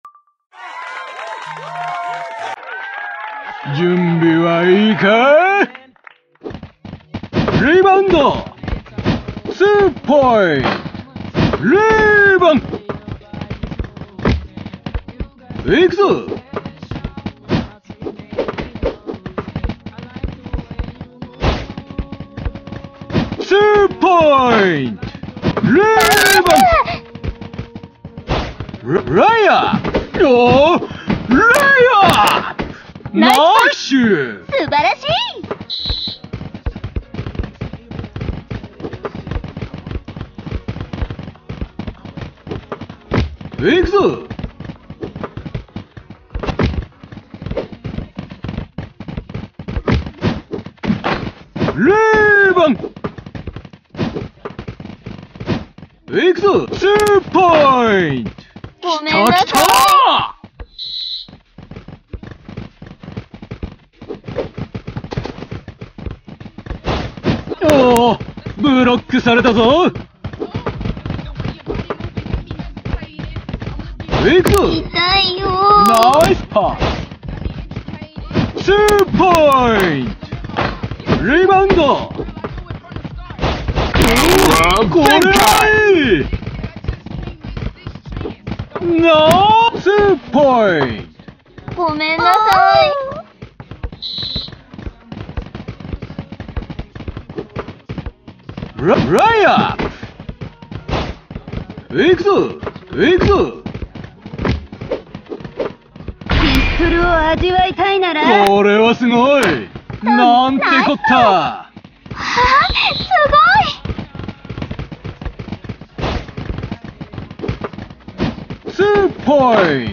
FeverDunk《フィーバーダンク》ランクマッチ(34) 3on3対戦ゲーム、アプリ《フィーバーダンク》のプレイ動画です。 実況は無しの試合している光景だけになりますのでご了承を。